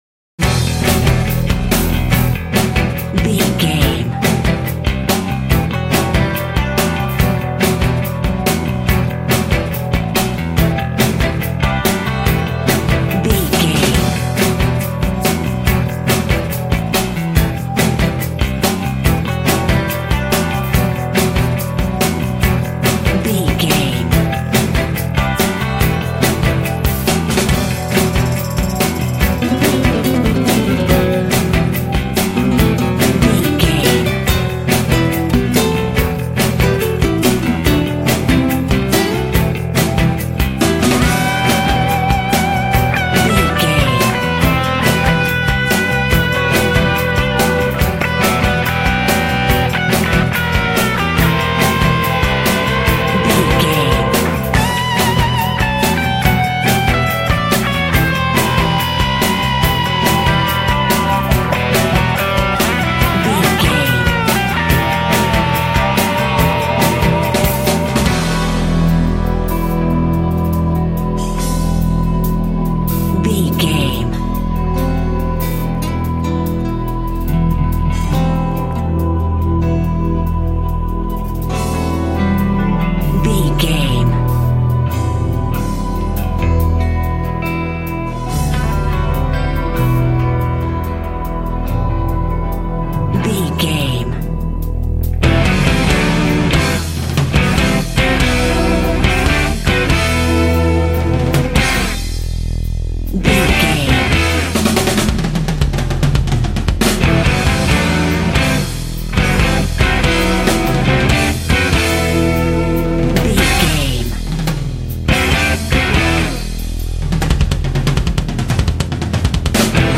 Aeolian/Minor
drums
electric guitar
bass guitar
Sports Rock
pop rock
hard rock
lead guitar
aggressive
energetic
intense
powerful
nu metal
alternative metal